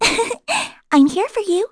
Mediana-vox-get_02.wav